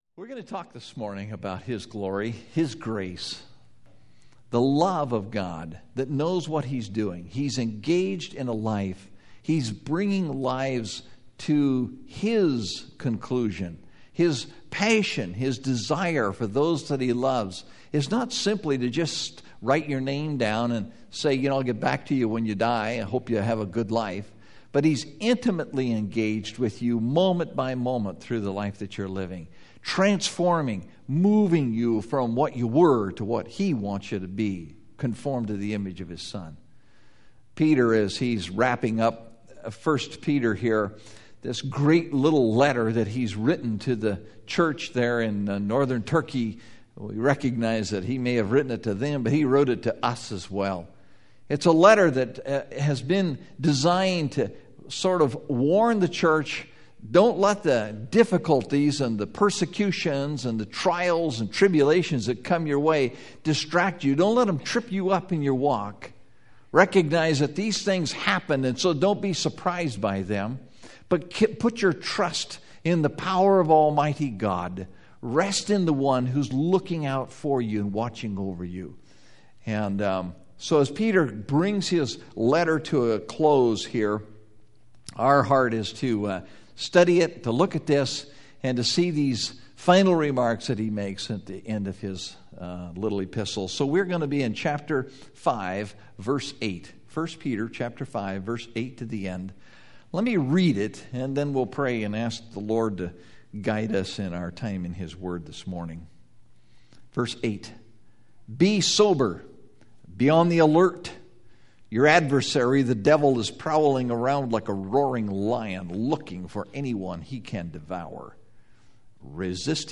Stand Fast (1 Peter 5:8-14) – Mountain View Baptist Church